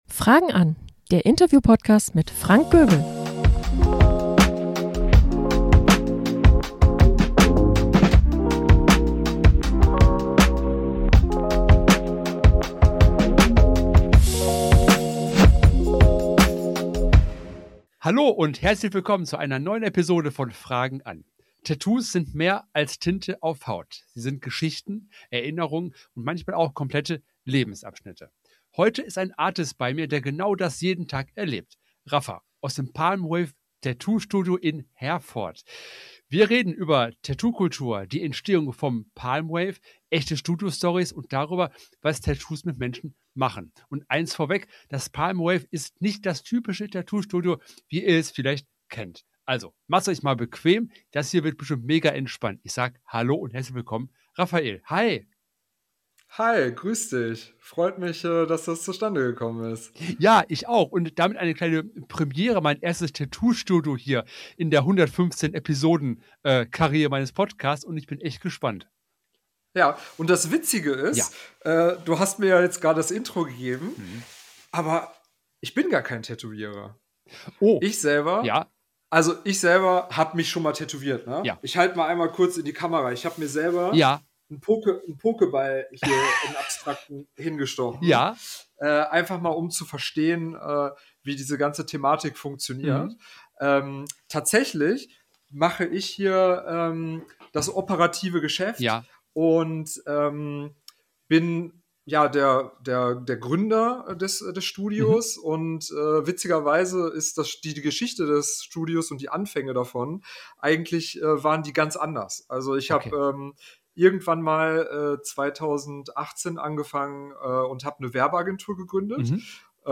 Wie baut man ein Tattoo-Studio auf? Das ist eine der Fragen, die mein Gast heute beantwortet.